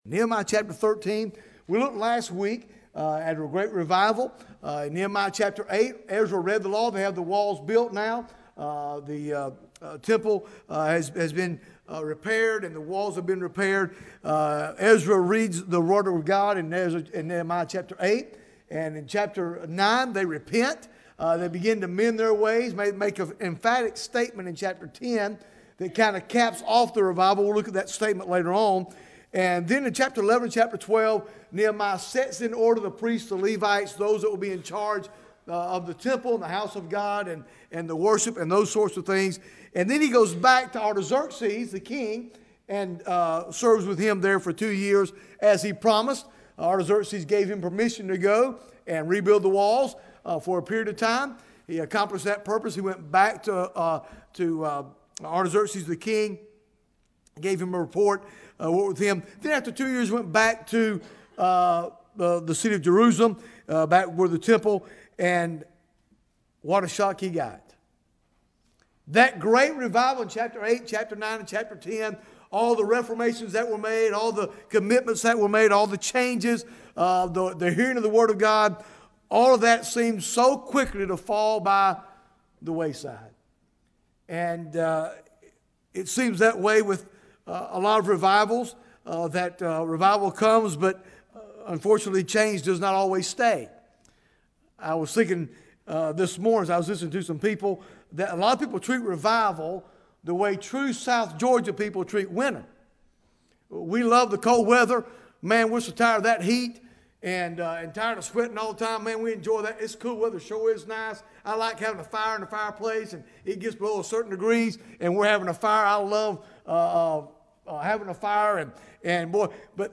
Bible Text: Nehemiah 13:7 | Preacher